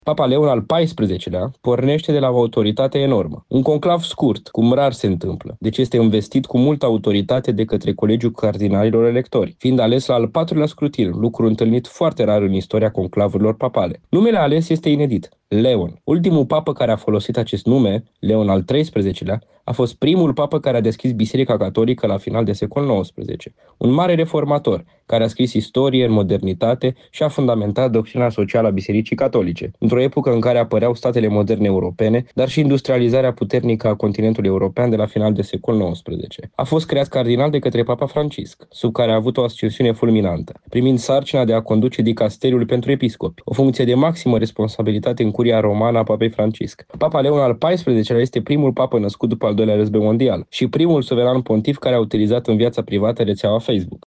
într-un comentariu oferit postului nostru de radio.